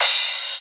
Crashes & Cymbals